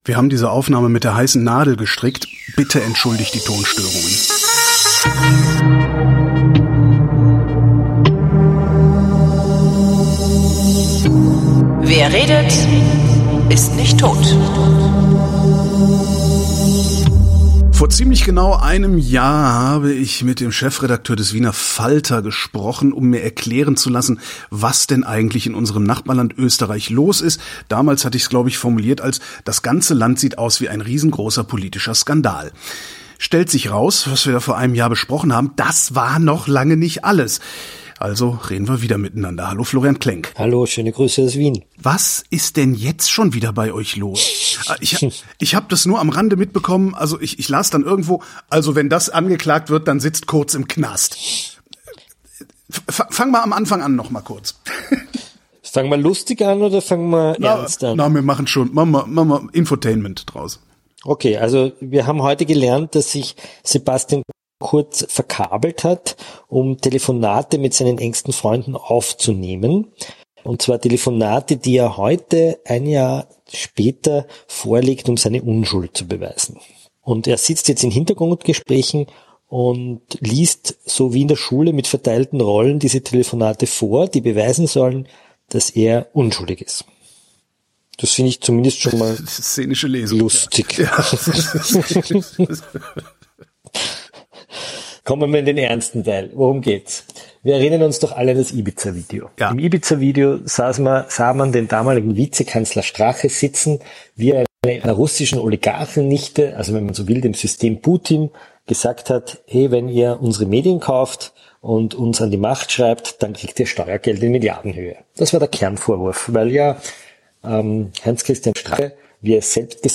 Bitte entschuldigt die Tonstörungen.